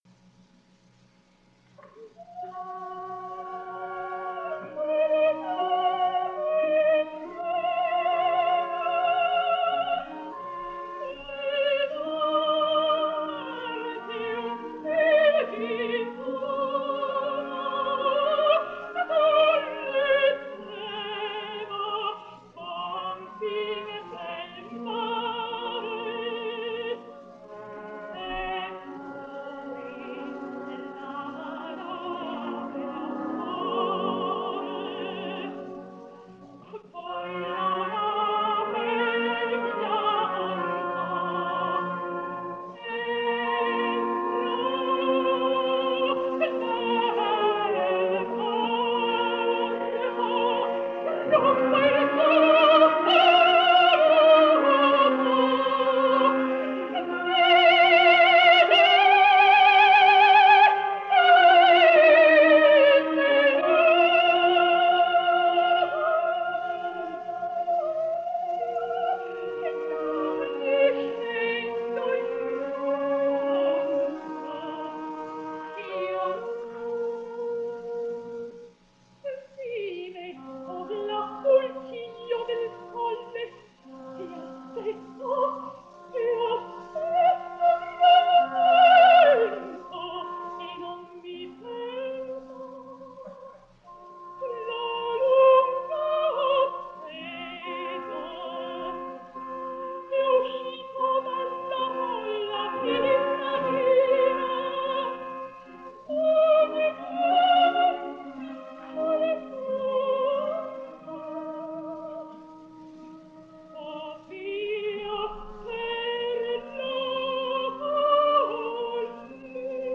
Жанр: Opera
итальянская оперная певица, сопрано.